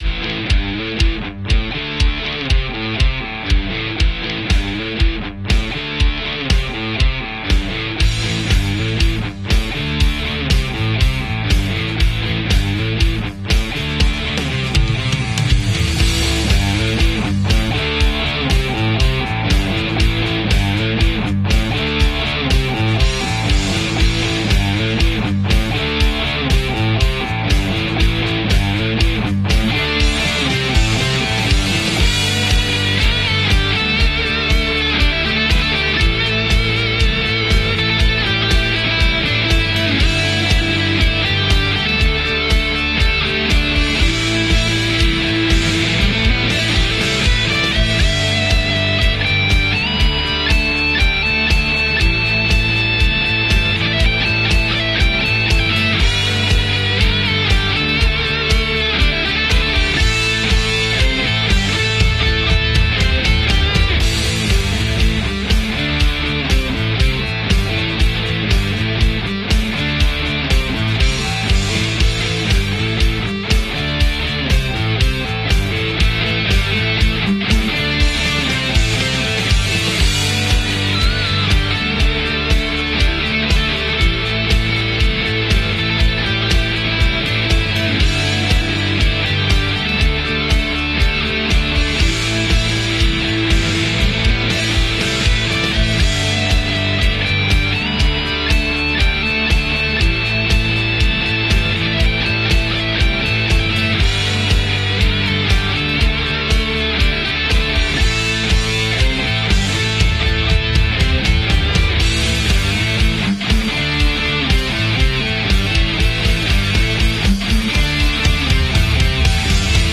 Realizamos la instalación de un full system en este Power Turbo Diesel, que incluye un Downpipe y un sistema de escape de alto rendimiento. Esta combinación optimiza el flujo de gases, mejorando el rendimiento del motor y dando como resultado un sonido más profundo y potente.